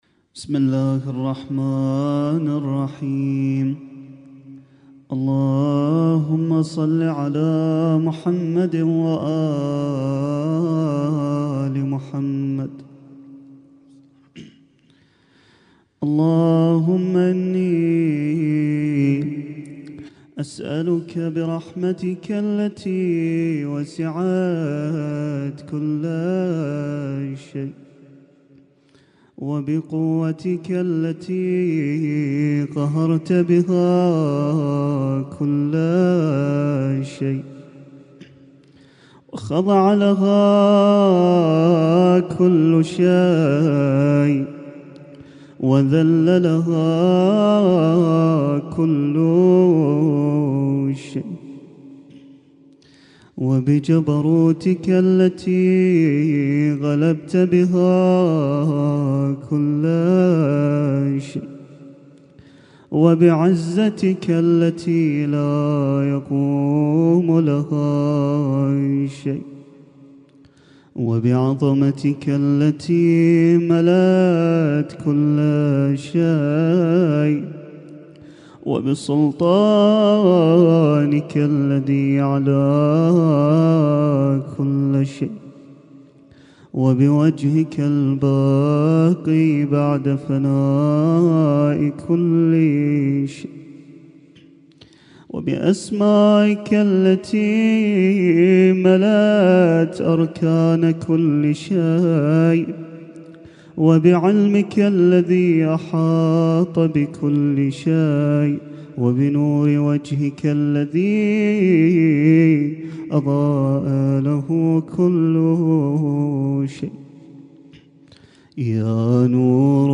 Husainyt Alnoor Rumaithiya Kuwait
اسم التصنيف: المـكتبة الصــوتيه >> الادعية >> دعاء كميل